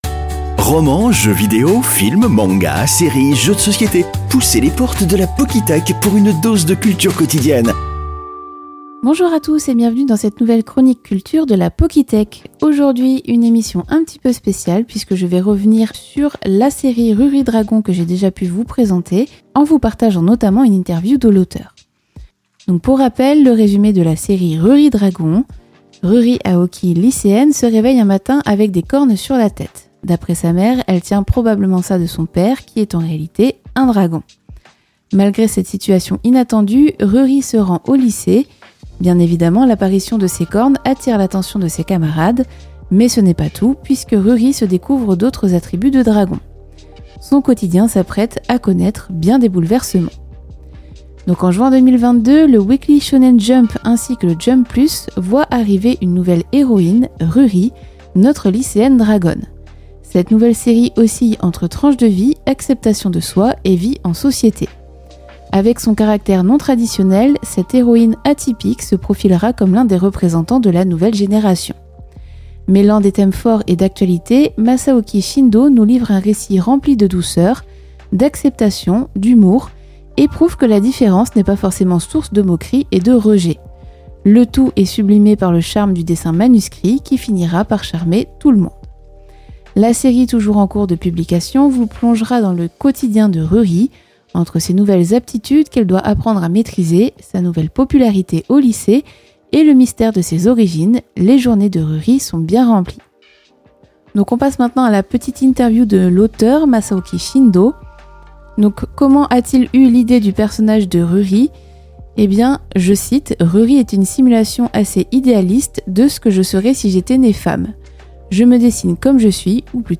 Petite interview